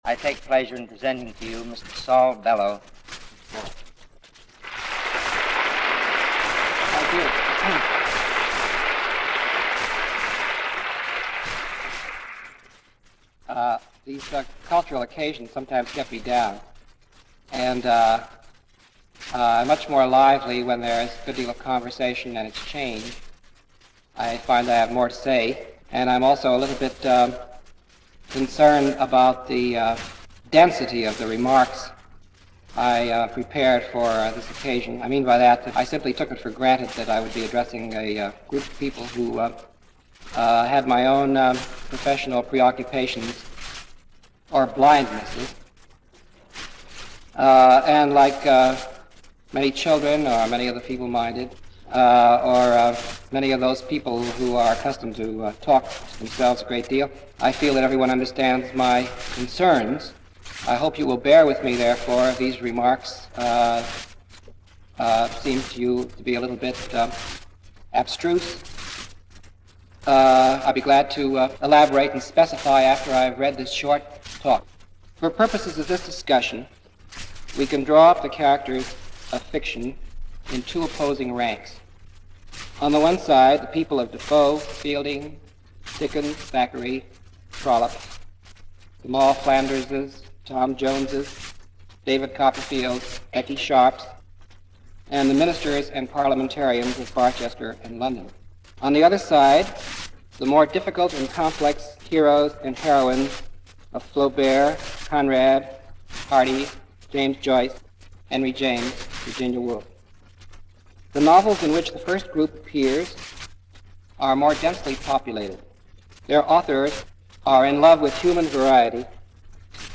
Saul Bellow – Lecture at Illinois State University – March 12, 1959 –
Saul Bellow this week, discussing the art of Fiction during a lecture at Illinois State University on March 12, 1959.